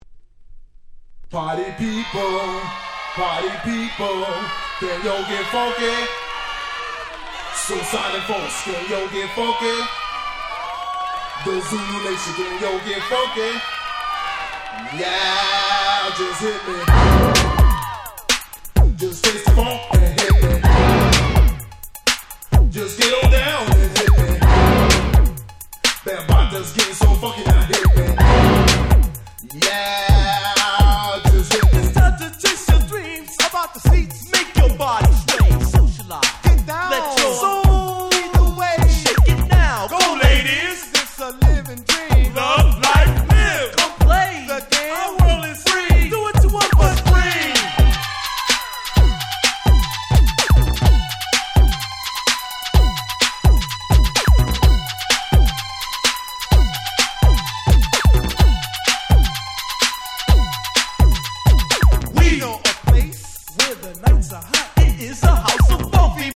82' Old School Hip Hop Super Classics !!
(Vocal)
80's オールドスクール